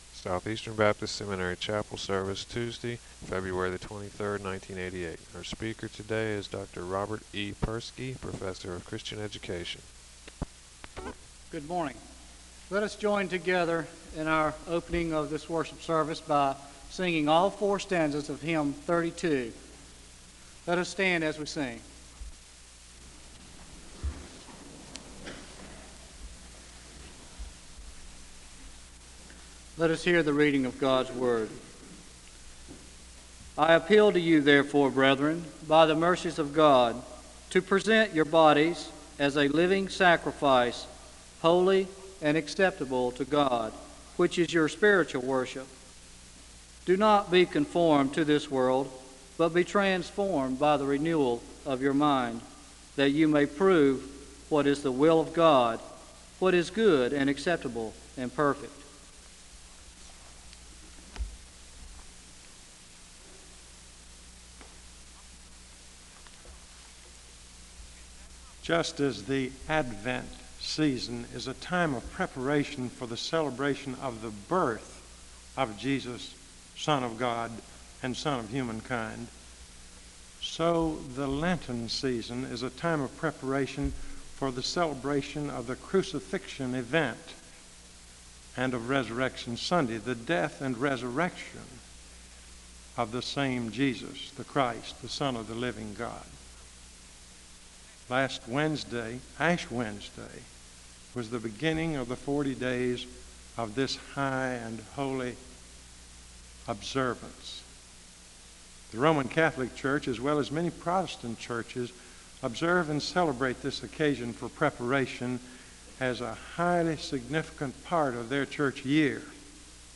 The service begins with a Scripture reading from Romans (0:00-1:01).
He argues that Lent calls one to reflect on his life (4:46-7:40). There is a litany for worship and sacrifice (7:41-8:52).
There is a Scripture reading and a litany for rejoicing in hope (12:26-15:00). There is a moment of prayer (15:01-15:41).